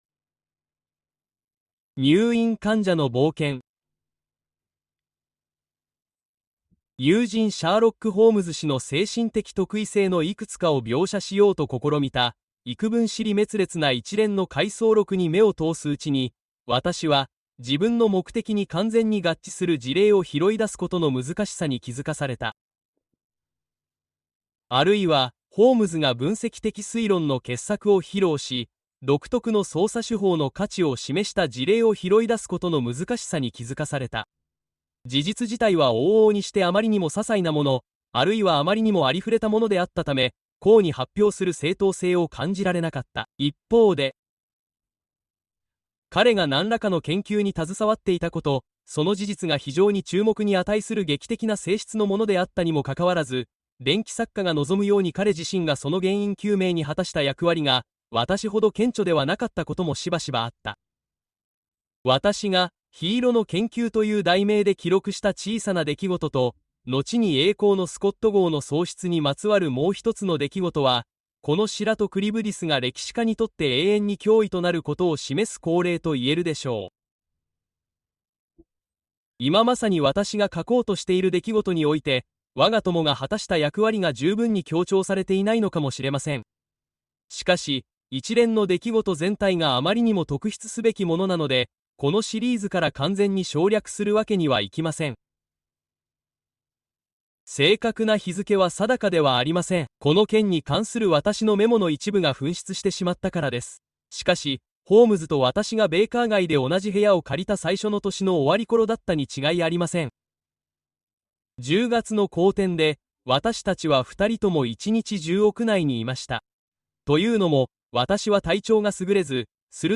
The Nightmare Room: Conan Doyle’s Creepiest Tales (Audiobook)